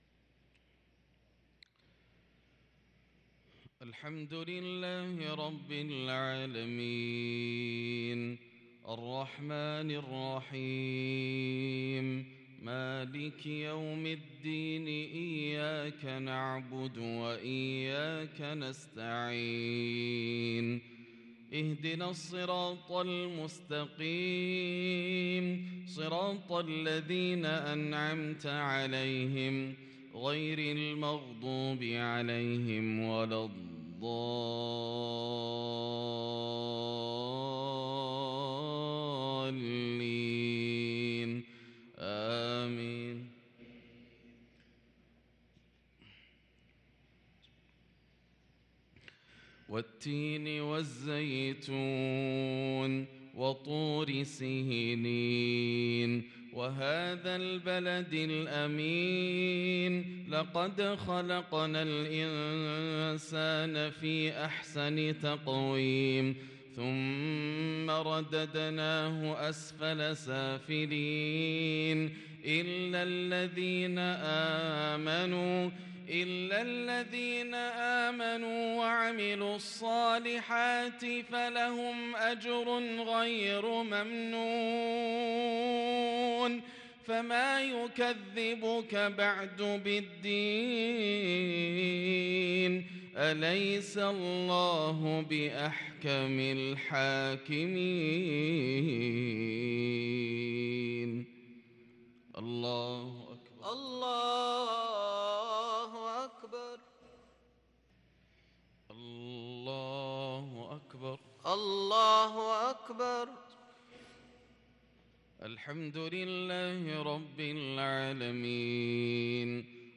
صلاة المغرب للقارئ ياسر الدوسري 8 ربيع الآخر 1444 هـ
تِلَاوَات الْحَرَمَيْن .